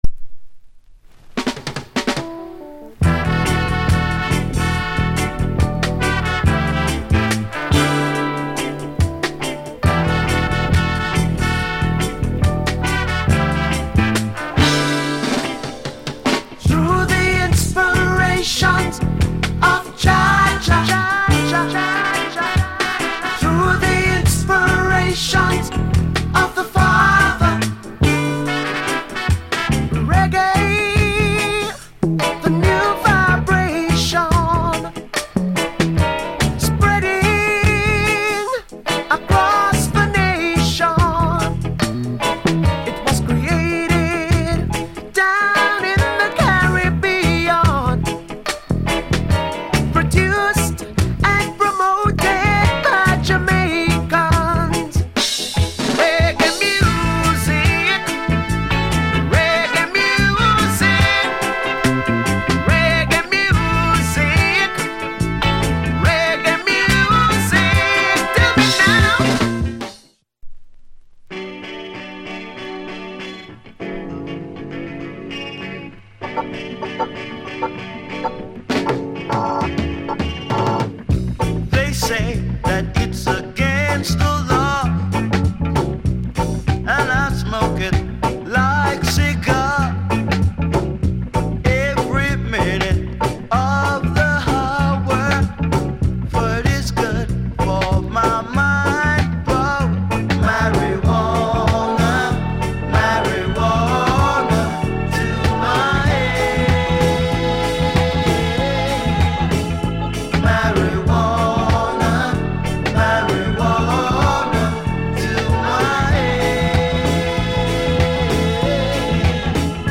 Genre Roots Rock / Male Vocal Group Vocal